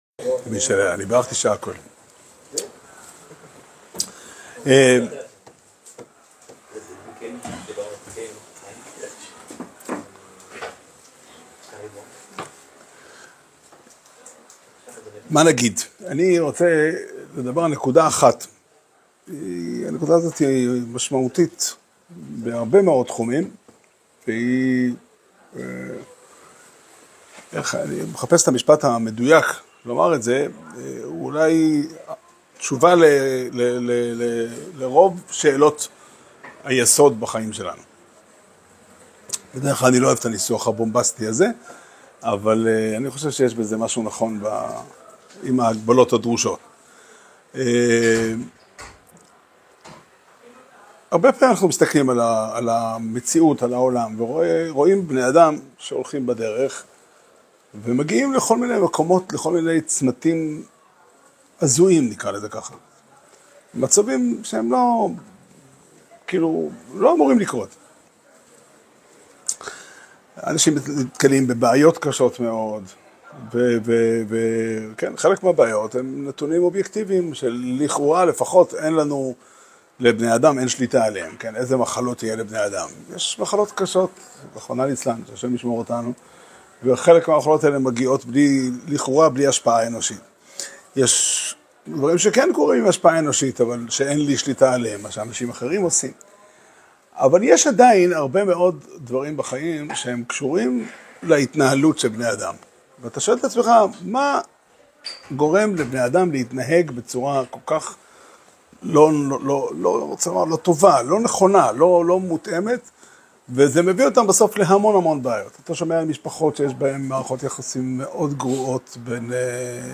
שיעור שנמסר בבית המדרש פתחי עולם בתאריך י"ח כסלו תשפ"ה